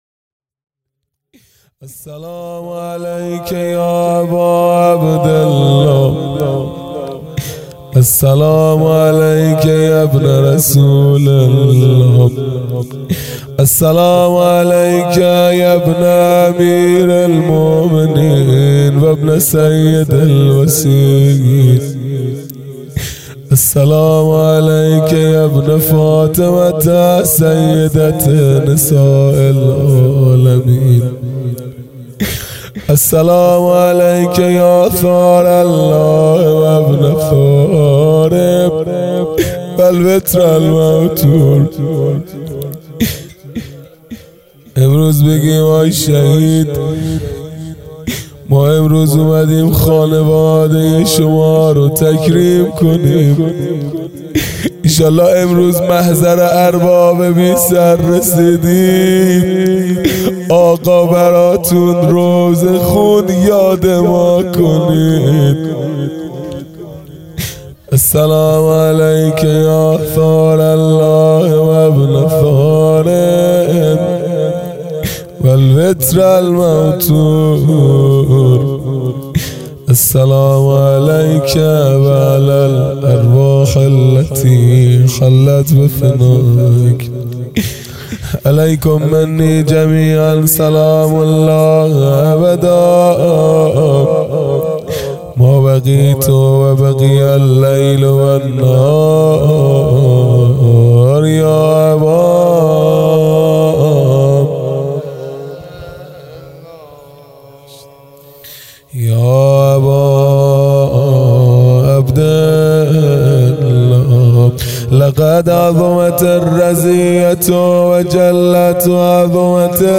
خیمه گاه - هیئت بچه های فاطمه (س) - زیارت عاشورا | ۶ مرداد ماه ۱۴۰۲
محرم الحرام ۱۴۴5 | صبح عاشورا